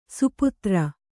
♪ suputra